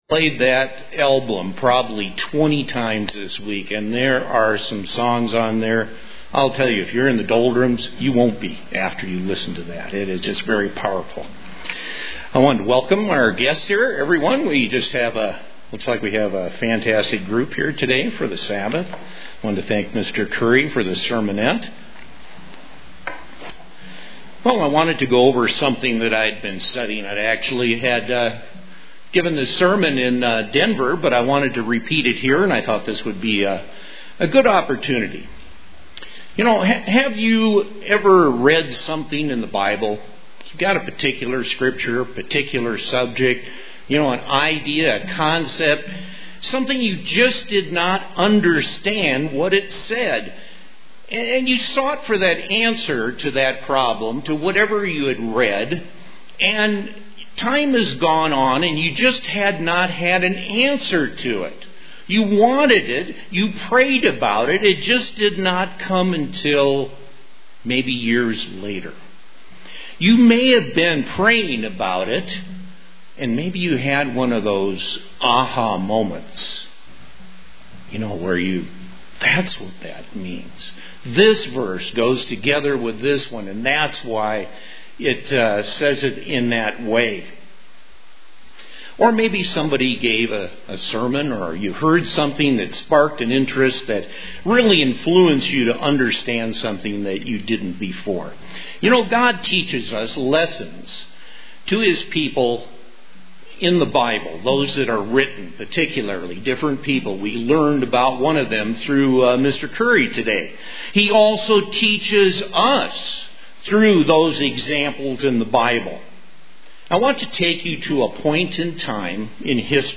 Print Outlines 9 points brought out in Daniel 9 that show us how to have more effective prayer UCG Sermon Studying the bible?